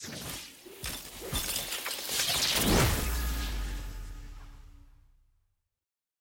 sfx-nacho-intro-skin-jinx-anim.lol_project_nacho_initial_banner.ogg